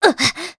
Aselica-Vox_Damage_jp_02.wav